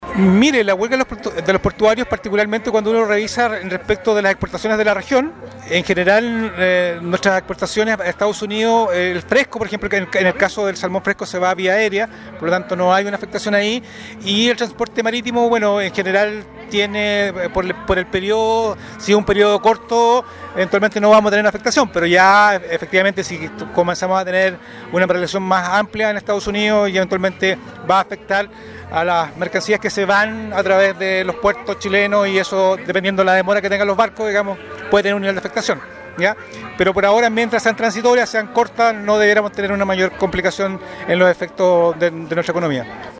Así lo expresó el Seremi de Economía Los Lagos, Luis Cárdenas